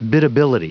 Prononciation du mot biddability en anglais (fichier audio)
Prononciation du mot : biddability